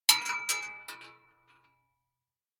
Bullet Shell Sounds
rifle_metal_6.ogg